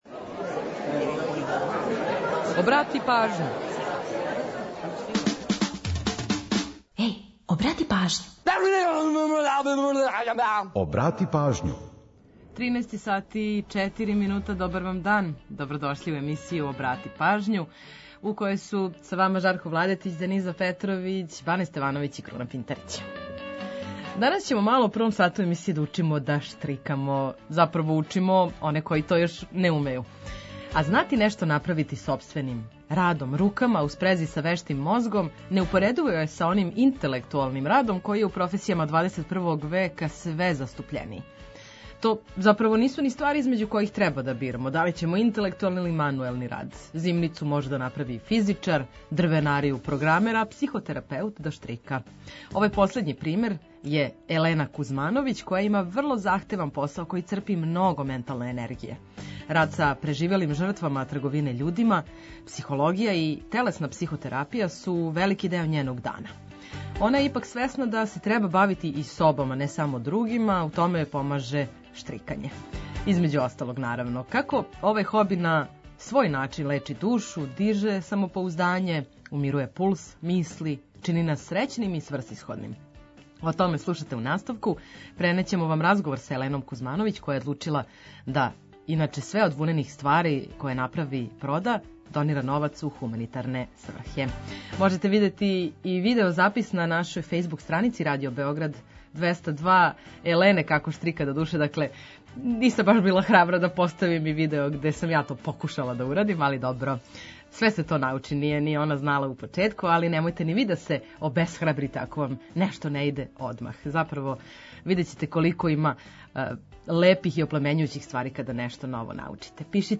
Сервисне информације и наш репортер са подацима о саобраћају помоћи ће многима у организовању дана, а „Културни водич” је ту да предложи које манифестације широм Србије можете да посетите.
Ту је и пола сата резервисаних само за нумере из Србије и региона.